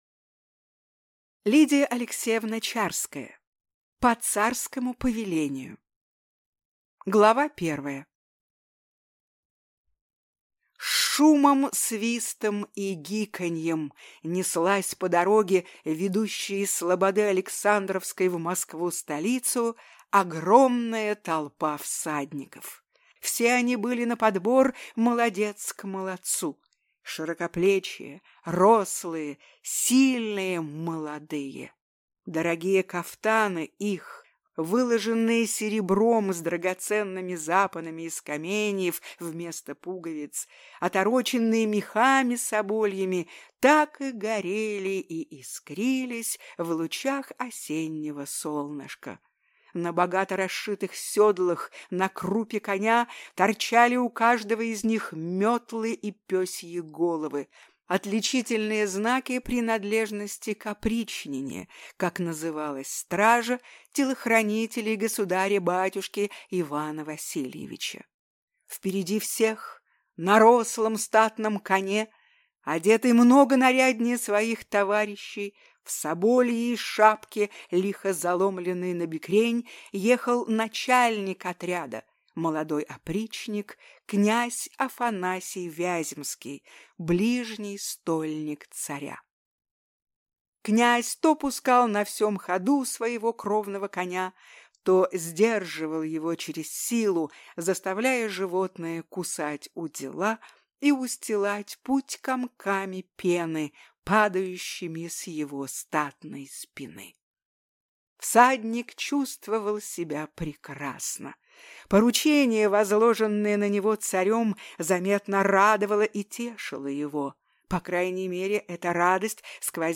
Аудиокнига По царскому повелению | Библиотека аудиокниг